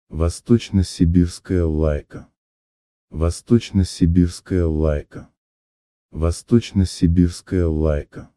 Say it in Russian: